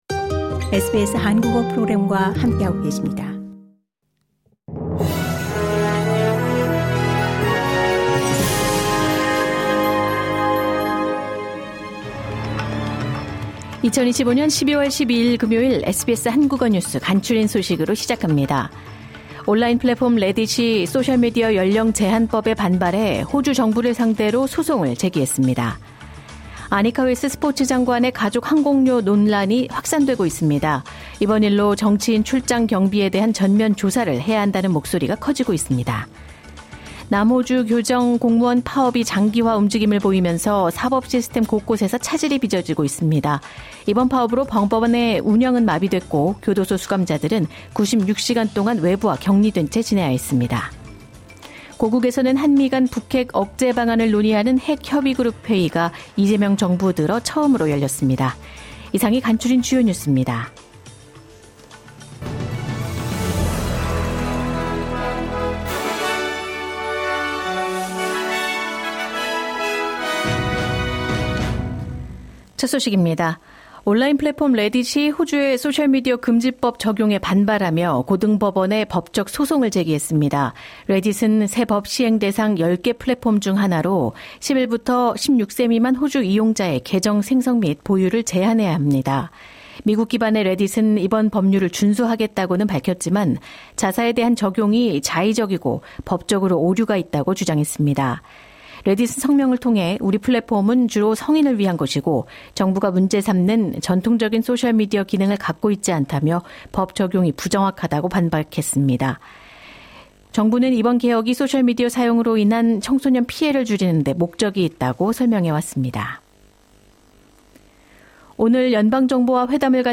매일 10분 내로 정리하는 호주 뉴스: 12월 12일 금요일